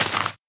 Sound Effect for Mining Blocks
If you have audio on your computer, you should now hear the familiar sound of a dirt block breaking when you click on a block.
dig_grass1.wav